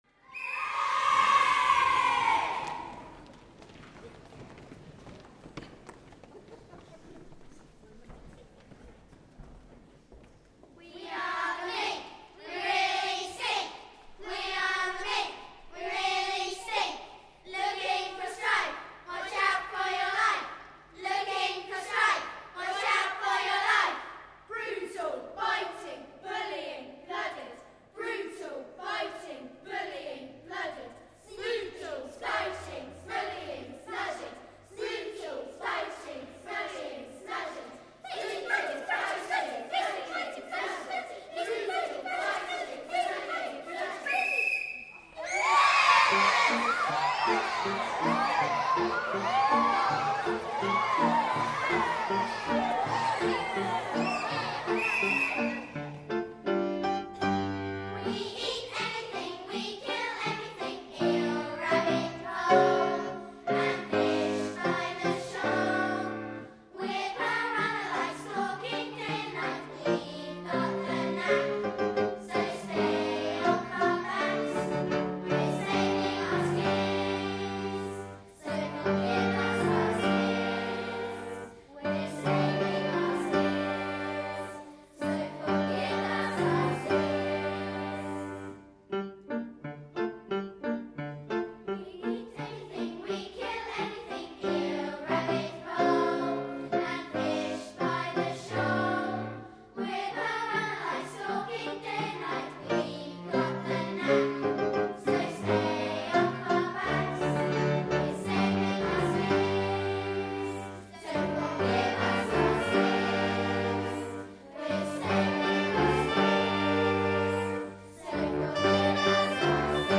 WCT joined other singers to perform the choral works as part of Otter – Lutra, Lutra on the Stour, in Wimborne Minster and Guildford Cathedral.